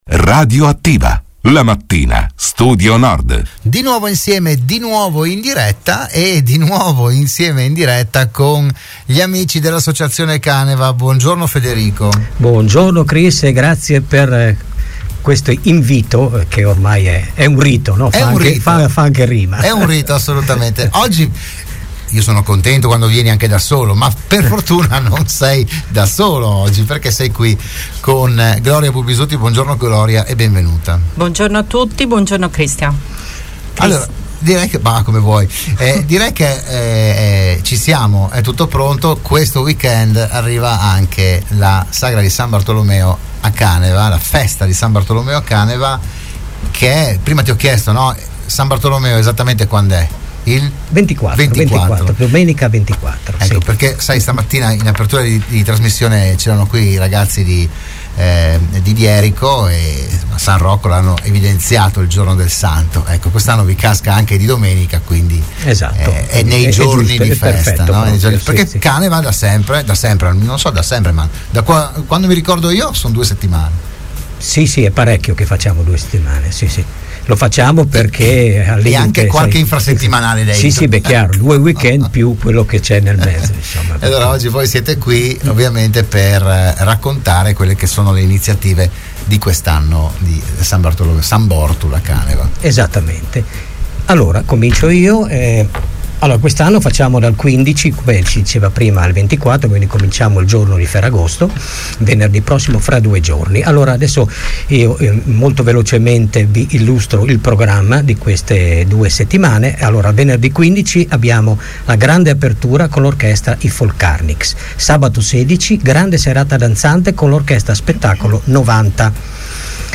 L’AUDIO e il VIDEO dell’intervento a Radio Studio Nord